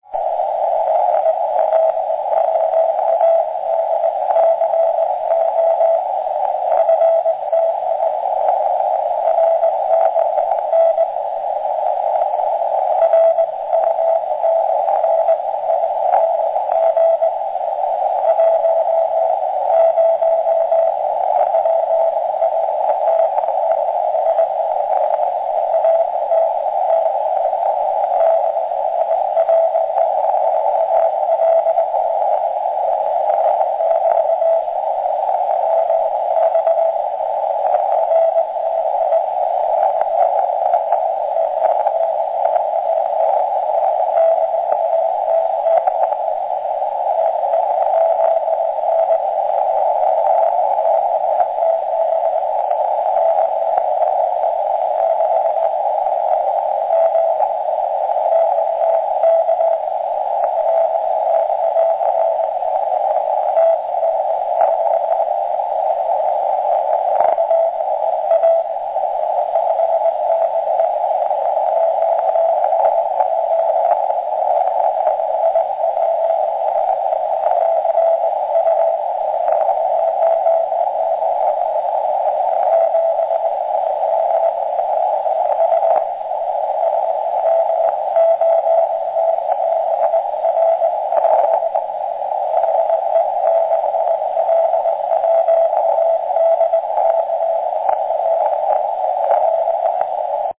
Conditions very poor (SN:36 SFI:78 A:22 K:5).